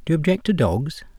The first three syllables of Do you object are spoken very fast.
And this wasn’t even sloppy colloquial speech with accompanying extraneous noise. It was scripted and then read aloud in soundproof studio conditions.
From [dəjuəbˈdʒektəˈdɒgz] (the whole thing lasting less than a second) you have to be able to recover Do you object to dogs?.